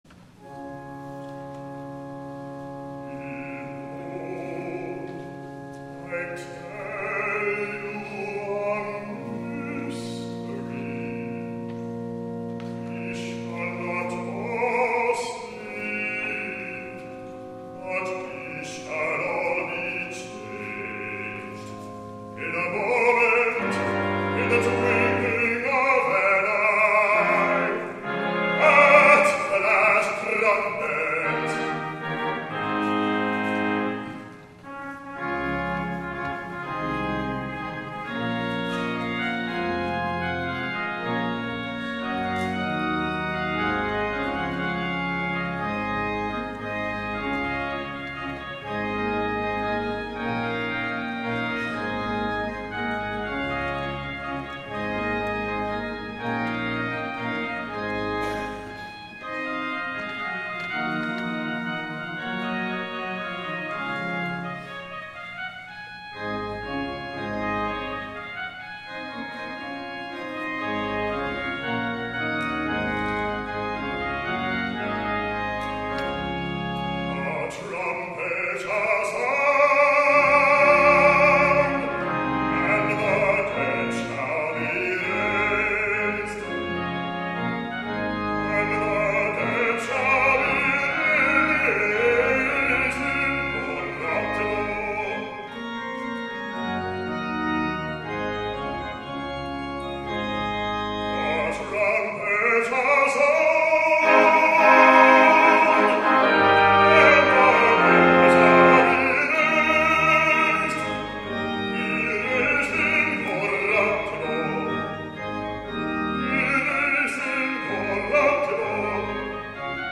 Morningside Presbyterian Church, Atlanta
2ND SUNDAY OF EASTER SUNDAY
THE SOLO
guest soloist